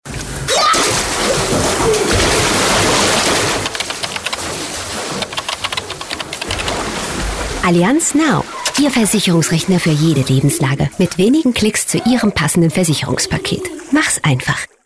Werbung Dallmayr